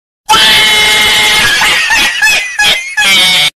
Sound Effects
Cat Laugh Meme 1